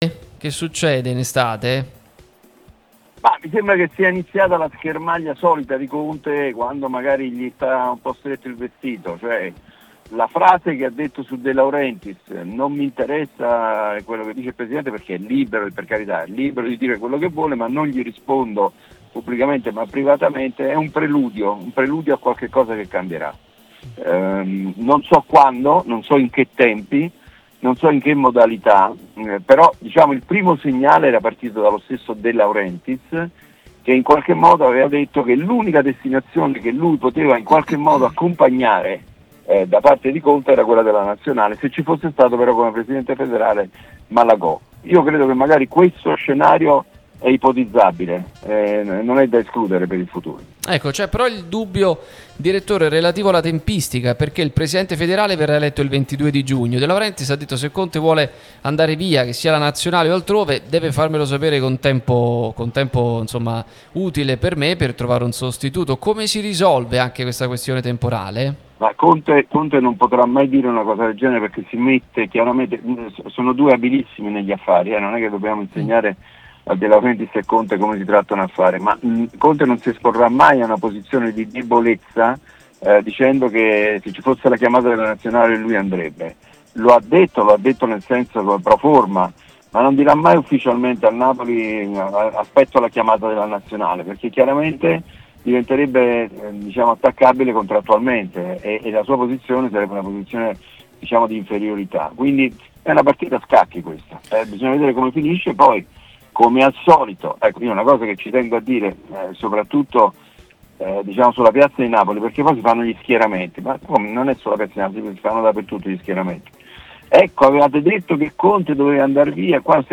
Podcast Conte via?